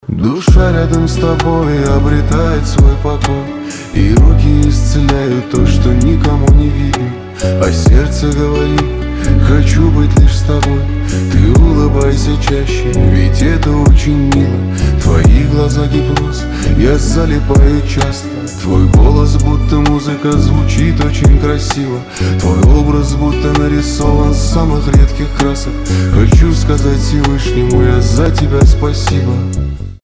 Романтические рингтоны
Поп